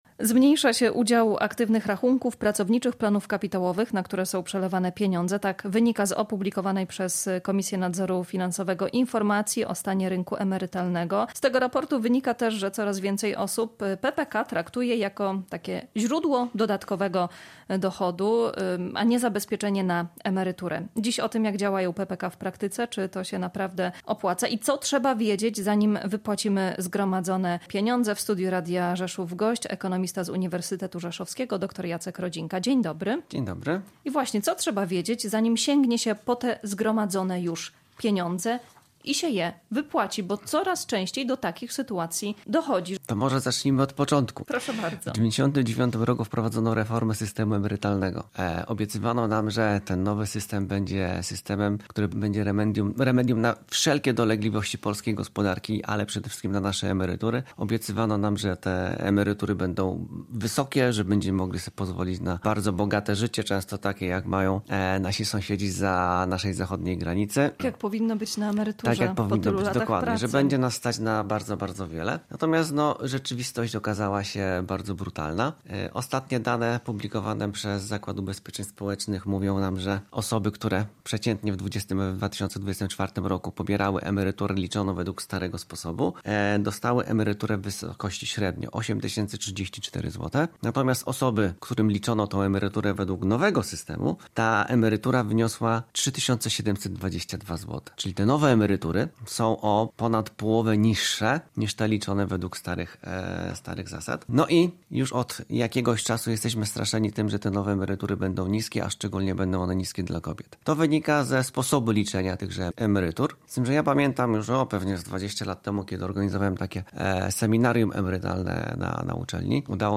Ekonomista-o-PPK.mp3